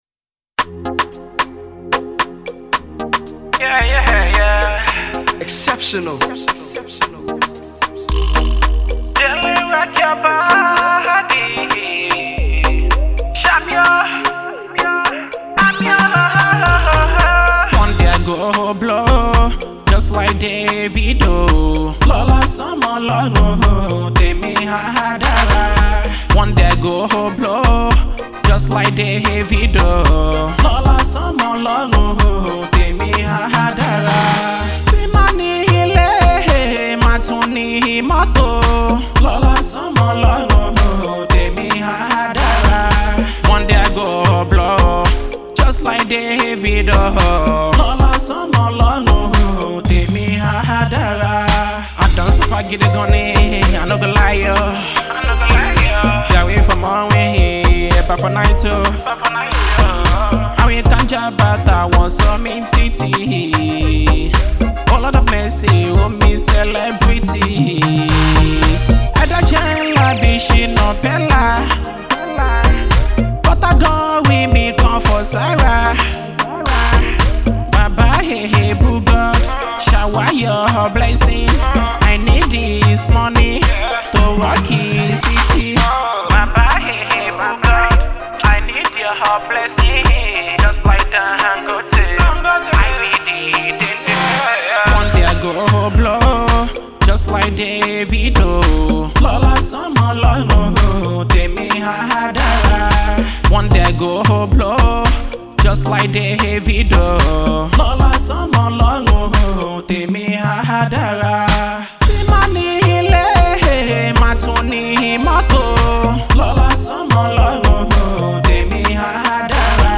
prayer song